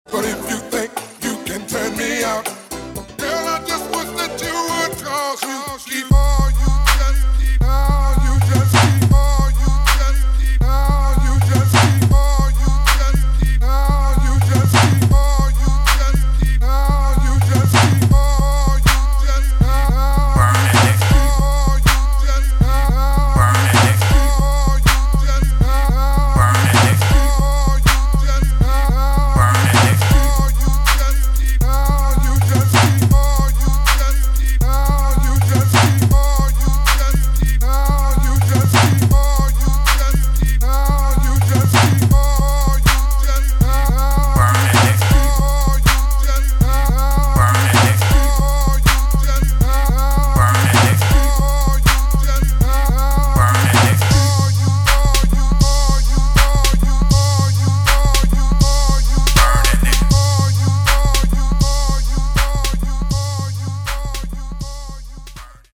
[ FOOTWORK / JUKE ]